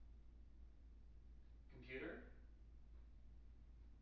wake-word
tng-computer-293.wav